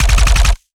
Added more sound effects.
GUNAuto_RPU1 C Burst_06_SFRMS_SCIWPNS.wav